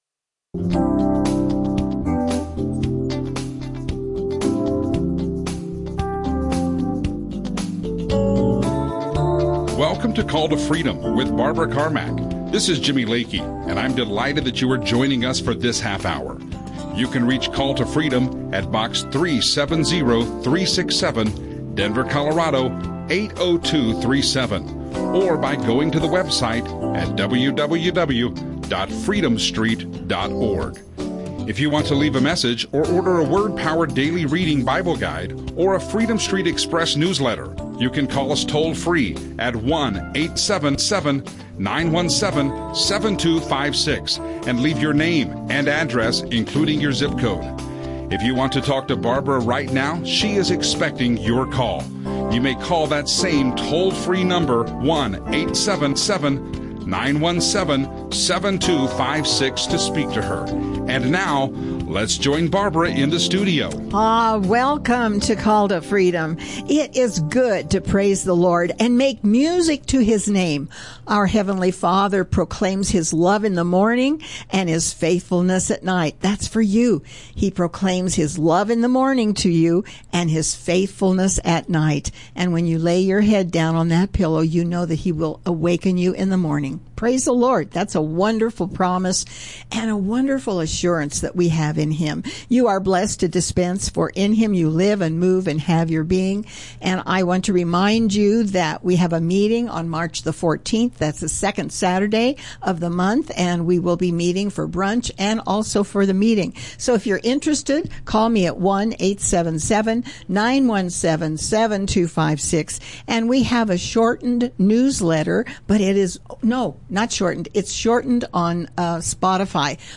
Audio teachings